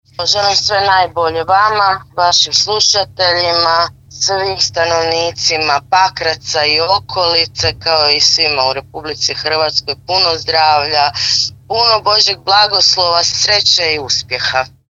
Pozvani ste na Dječji doček Nove godine u Pakrac a čestitku za 2025. upućuje svima gradonačelnica Pakraca Anamarija Blažević: